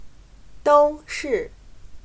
都是/Dōu shì/Todos